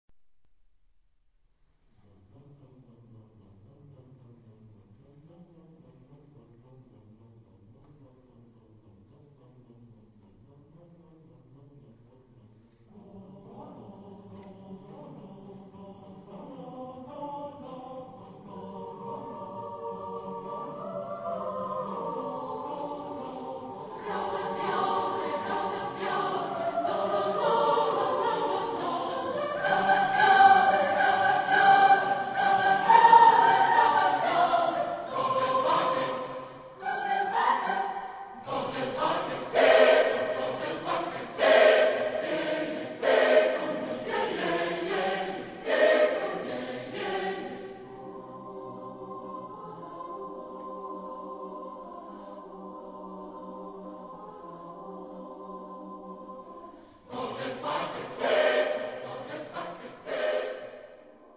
Type of Choir: SATB  (4 mixed voices )
sung by European Youthchoir 1999